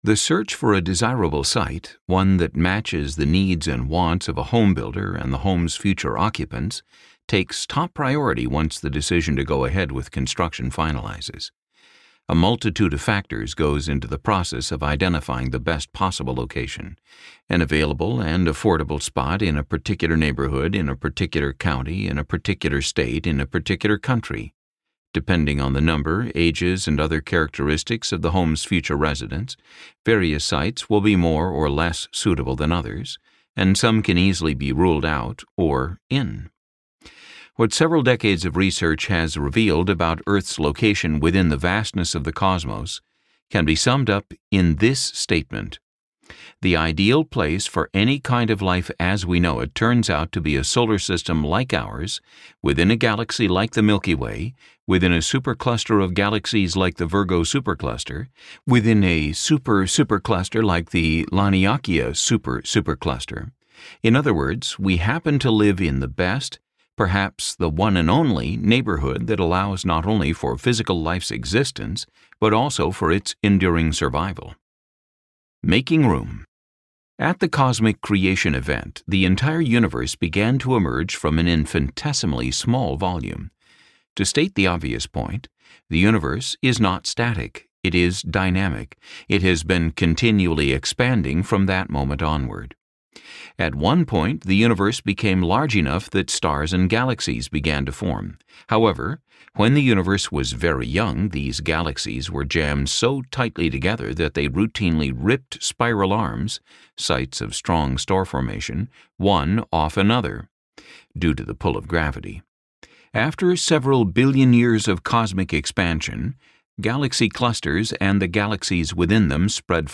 Improbable Planet Audiobook